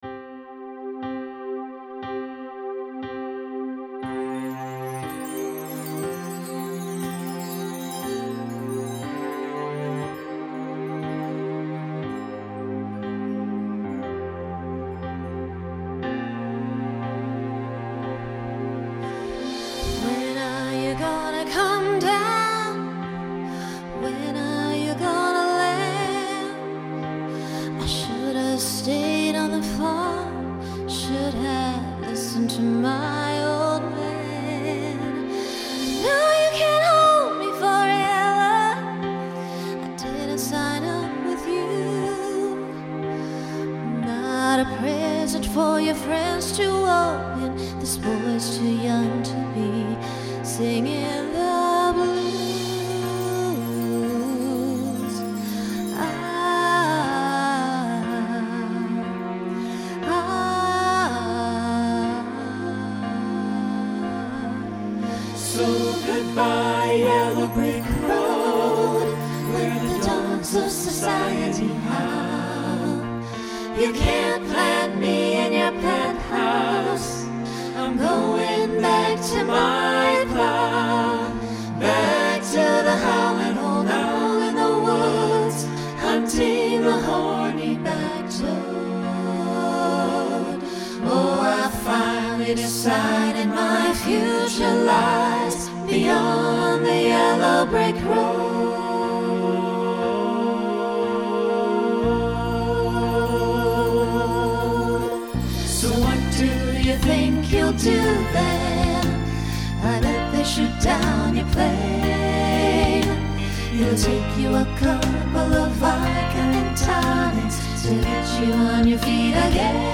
Genre Pop/Dance Instrumental combo
Ballad Voicing SATB